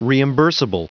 Prononciation du mot reimbursable en anglais (fichier audio)
reimbursable.wav